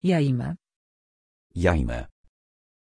Aussprache von Jaimè
pronunciation-jaimè-pl.mp3